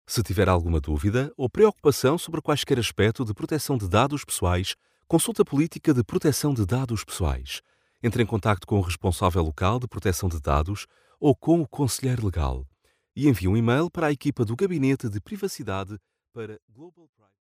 E-learning
Soy locutor profesional a tiempo completo con estudio de calidad broadcast.
Cabina de audio Vicoustic, micrófono Neumann TLM103, preamplificador Neve 1073SPX, interfaz Apogee Duet 2, Internet por cable de alta velocidad.
BarítonoBajoProfundoBajoMuy bajo
AmistosoCálidoTranquiloAtractivoConfiableConversacionalCorporativo